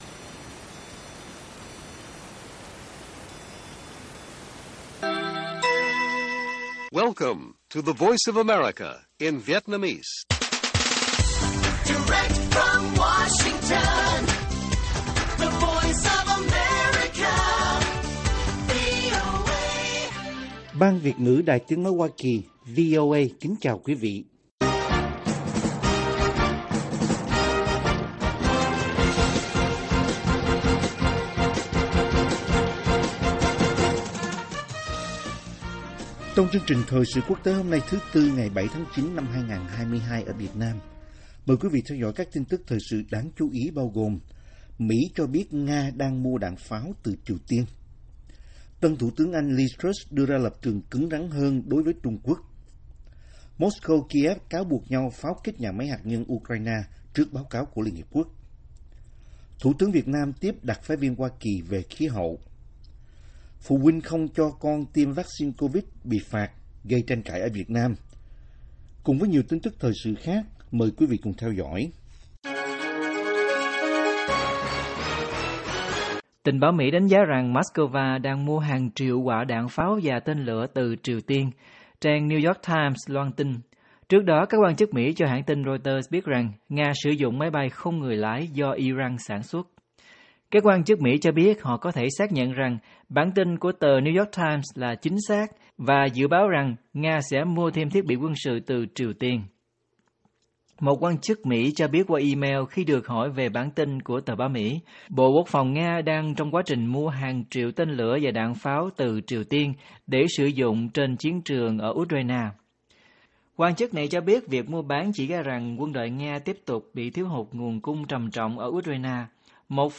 Mỹ cho biết Nga đang mua đạn pháo từ Triều Tiên - Bản tin VOA